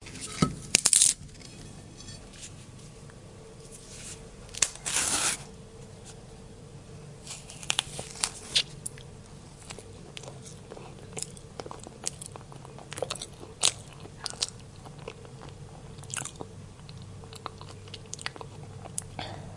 食品 " 香蕉 泪水 05
描述：撕开香蕉皮。 用Tascam DR40录制。
标签： 香蕉 水果 香蕉果皮 去皮 去皮 食品 剥离
声道立体声